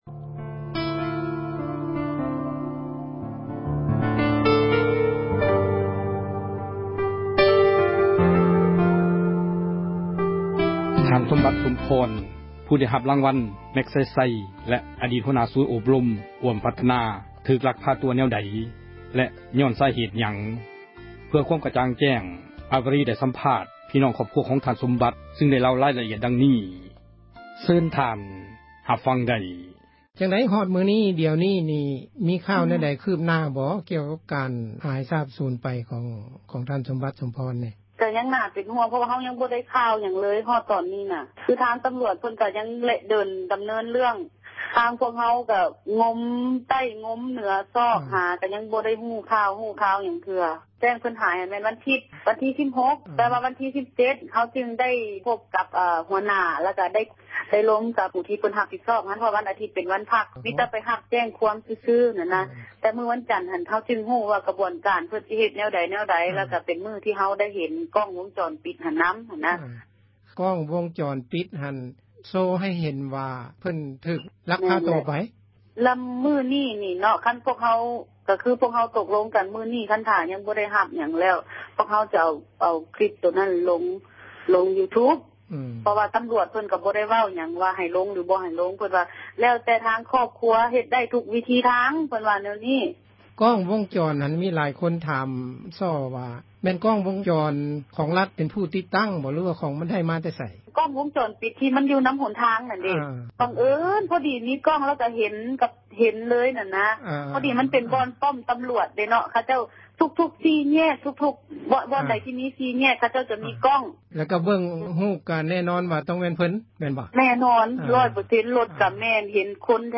ສັມພາດພີ່ນ້ອງ ທ.ສົມບັດ ສົມພອນ